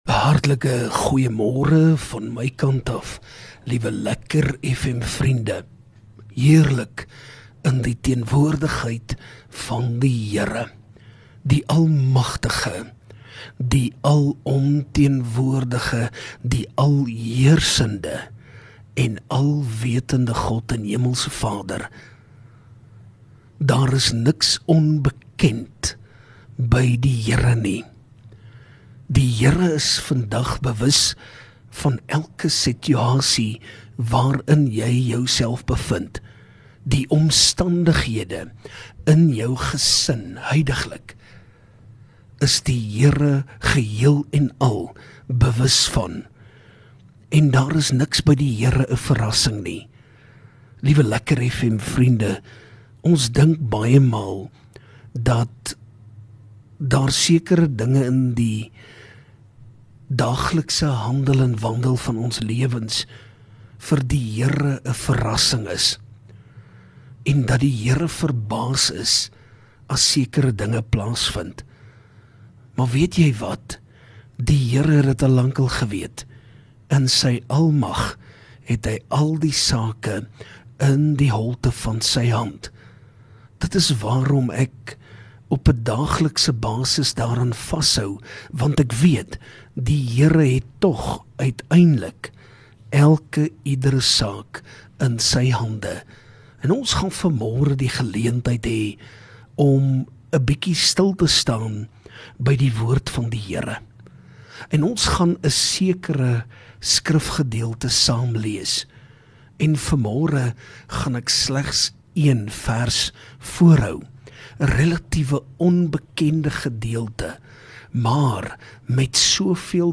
Oggendoordenking